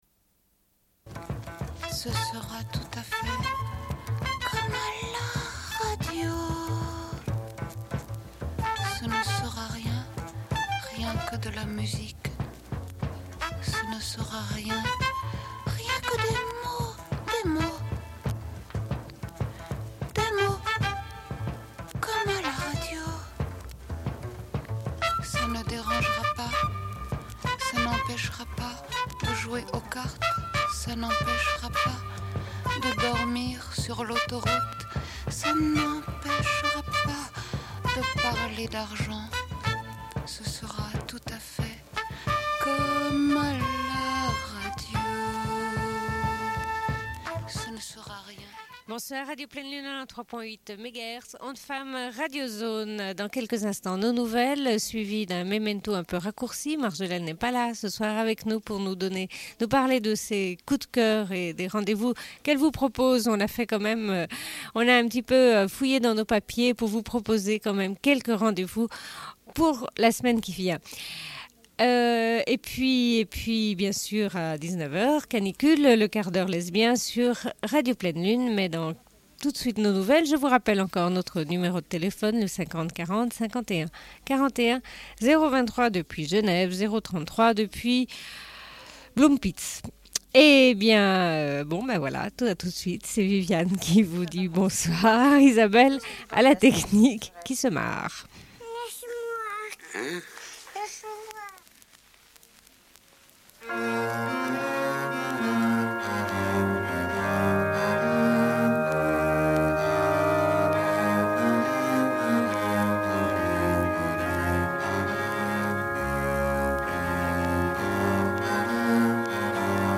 Une cassette audio, face B29:03